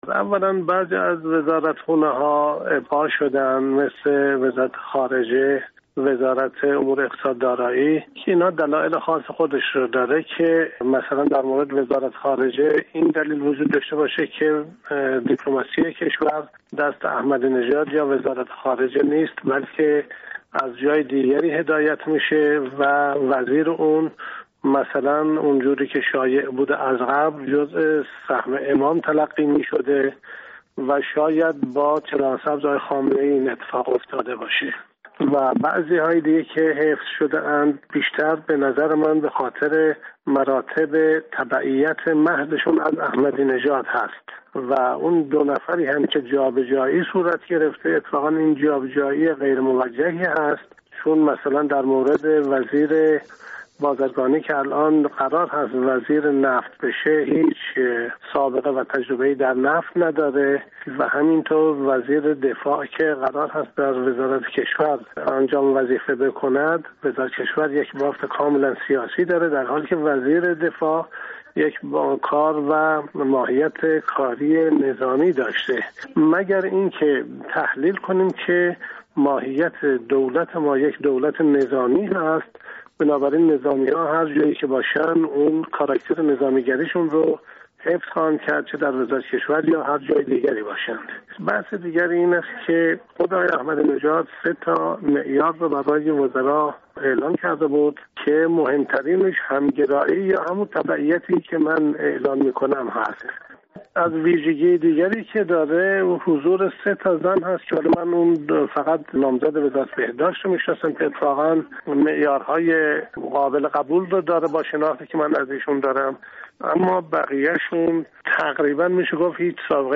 گفت‌وگو با قاسم شعله‌سعدی؛ نماینده پیشین مجلس شورای اسلامی